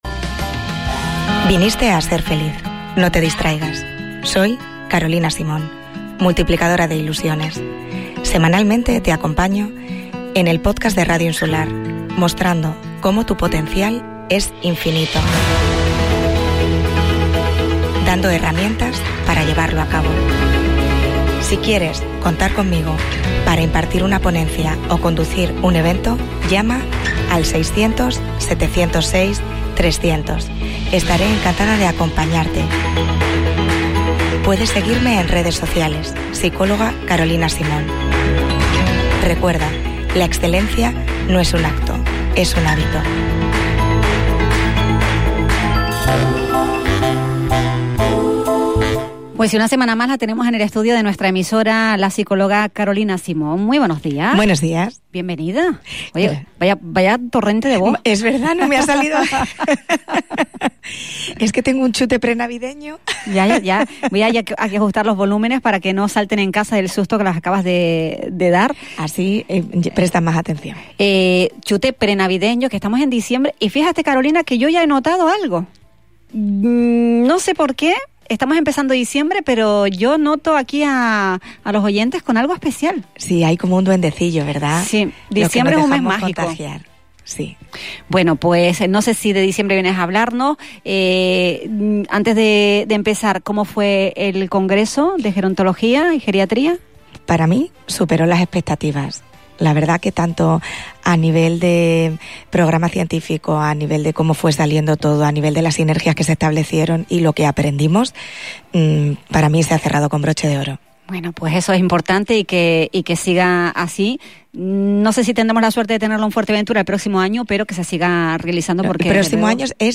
Con su habitual cercanía y sentido del humor